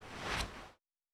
Ball Throw Normal.wav